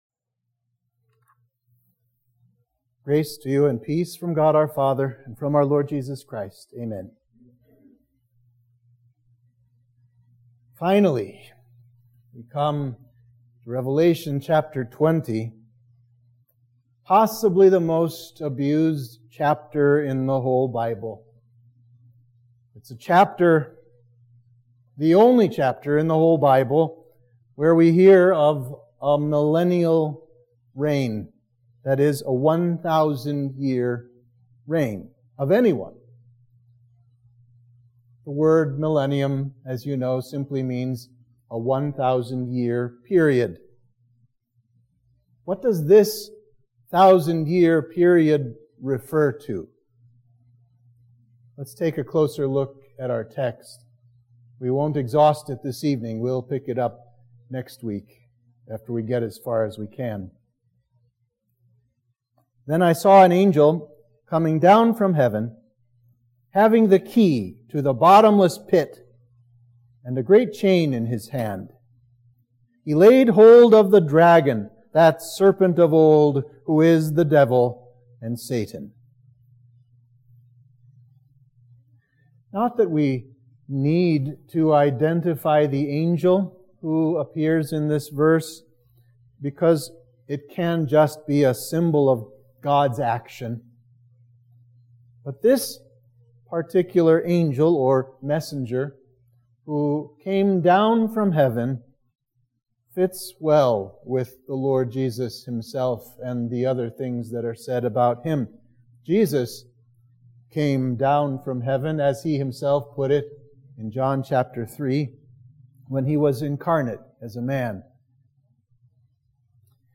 Sermon for Midweek of Trinity 18